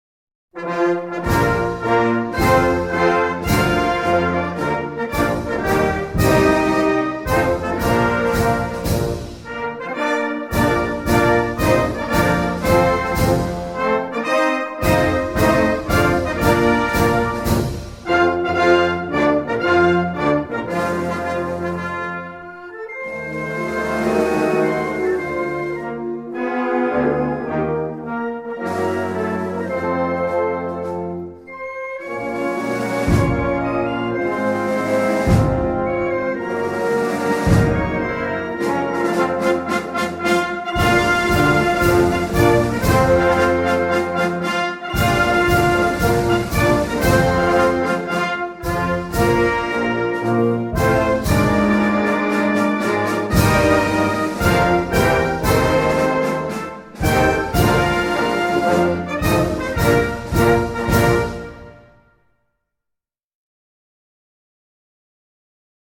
French_anthem.mp3